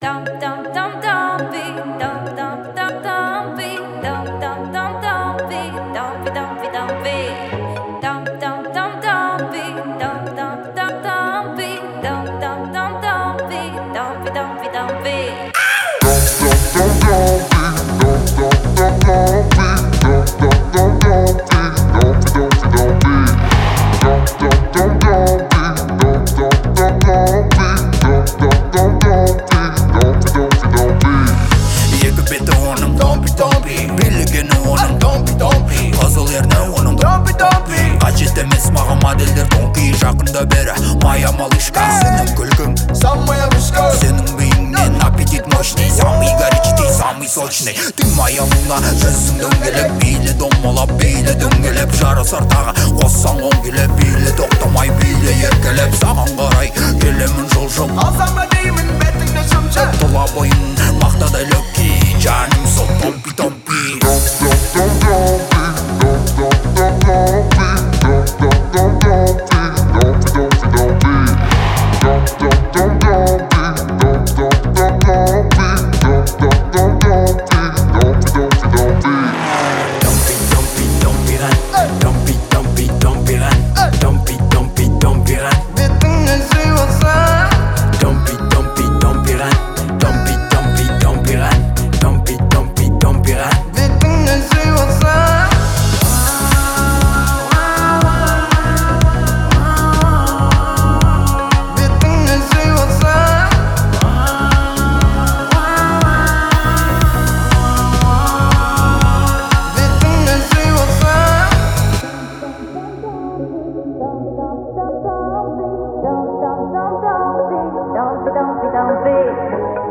это динамичная и зажигательная композиция в жанре хип-хоп